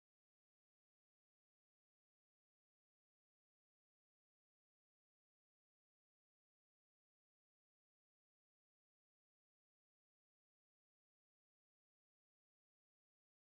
T 95 next gen. tank (Prototype) sound effects free download